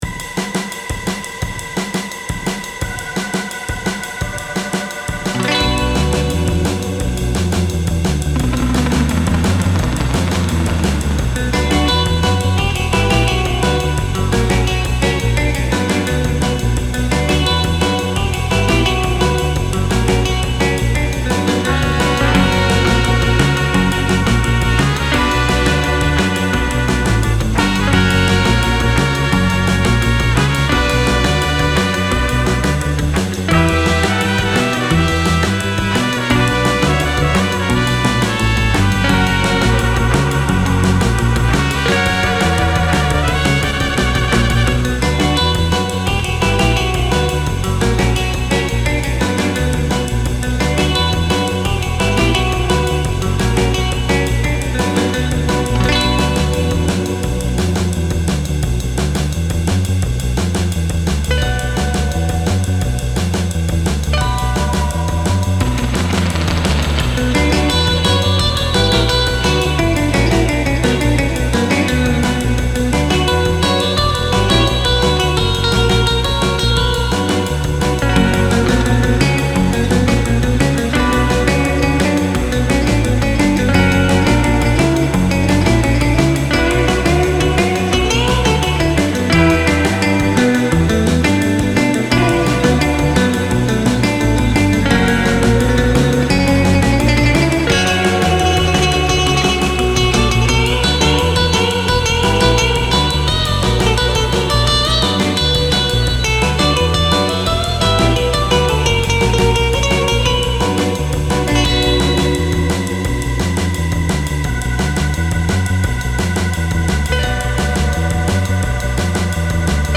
Но стиль - сто пудов из конца 50-х -- начала 60-х.
А стиль да - явно сёрф рубежа 50х\60х
Слишком "скрупулёзная" и "чистая" гитара.
Слегка напоминает коллаж из знакомых известных тем.
Слишком чисто все исполнено - вряд ли вживую.